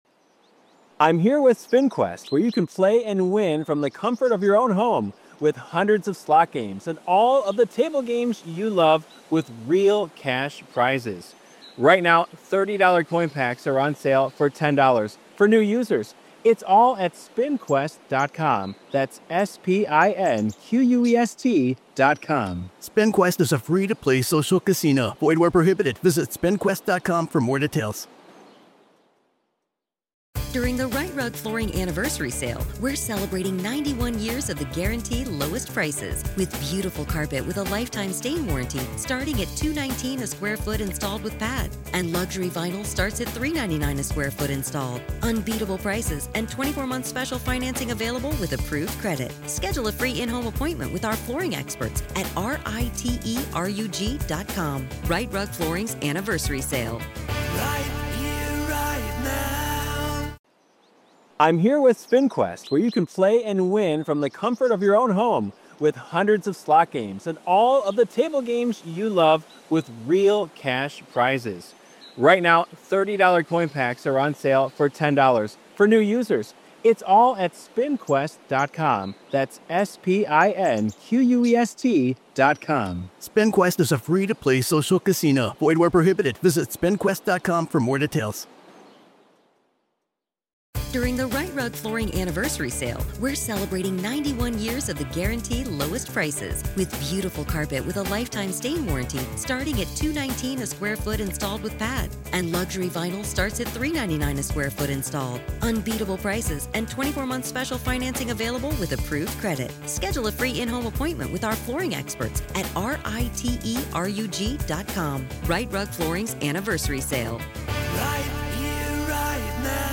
This is Part Three of our conversation.